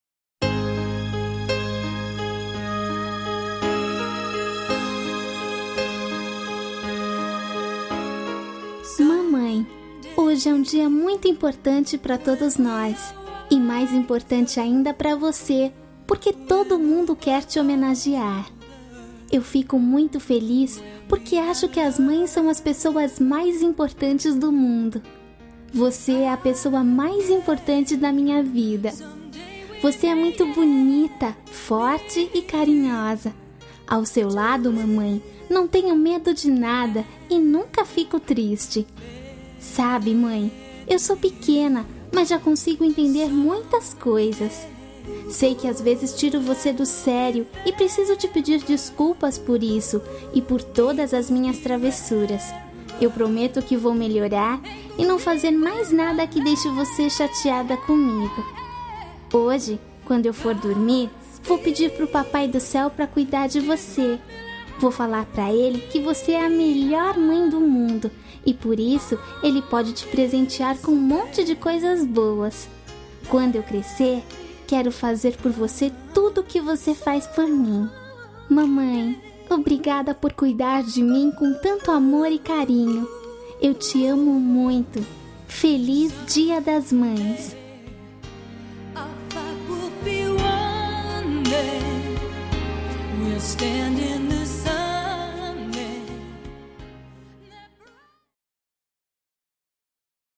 Voz Menina